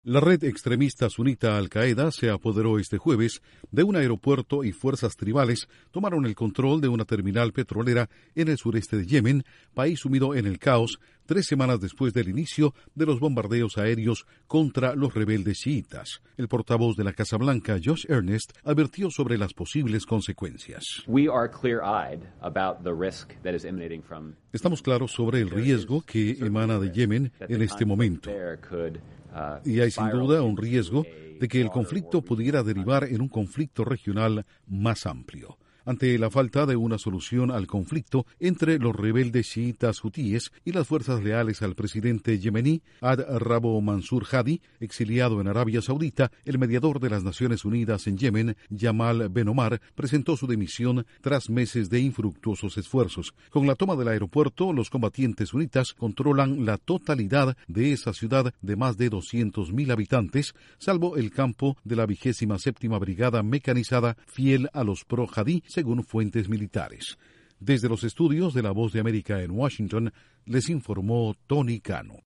La Casa Blanca advierte sobre un posible conflicto de grandes proporciones luego de que Al Qaeda se apoderara de un aeropuerto y una terminal petrolera en Yemen. Informa desde los estudios de la Voz de América en Washington